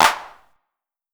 Claps
JJClap (16).wav